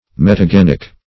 metagenic - definition of metagenic - synonyms, pronunciation, spelling from Free Dictionary Search Result for " metagenic" : The Collaborative International Dictionary of English v.0.48: Metagenic \Met`a*gen"ic\ (m[e^]t`[.a]*j[-e]n"[i^]k), a. (Biol.)
metagenic.mp3